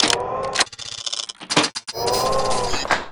MinigunReload.wav